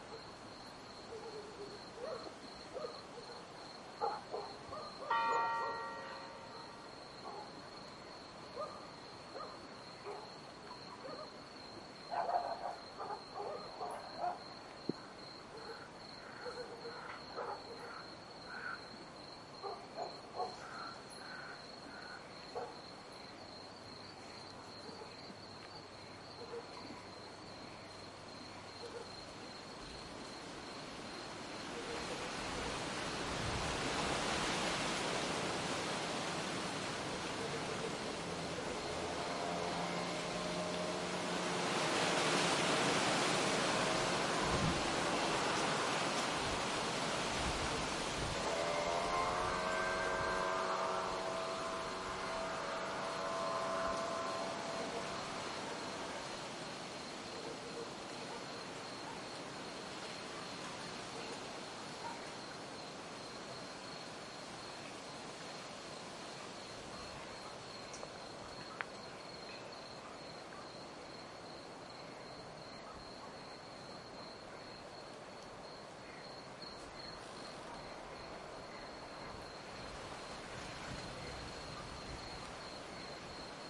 随机" 蟋蟀 乡村田野之夜，附近的沙哑的蝉来回走动，偶尔有远处的狗叫声
描述：蟋蟀国家田野之夜与附近刺耳的蝉来回+偶尔远处吠叫dog.flac
Tag: 蟋蟀 晚上 国家